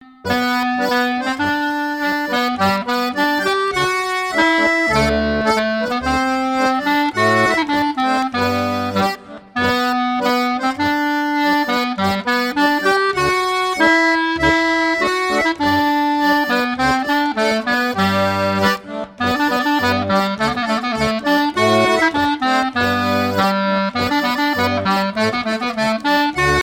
Marche nupiale - 2è version
fiançaille, noce
Pièce musicale éditée